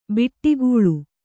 ♪ biṭṭigūḷu